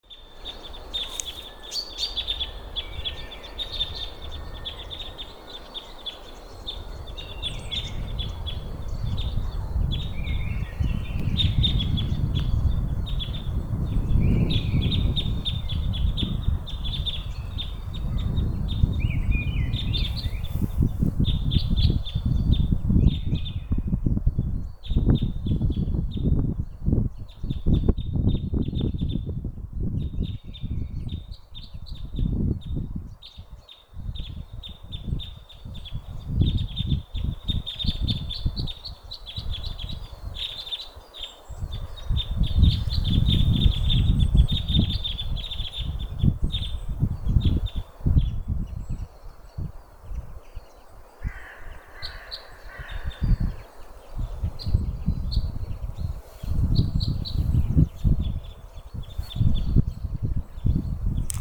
клест-сосновик, Loxia pytyopsittacus
Administratīvā teritorijaDundagas novads
Примечания/sākotnēji 4 lokāli, noteikti pēc balss gan vizuāli, sasauc vēl trīs barus, tad vienā lielā barā aizlido.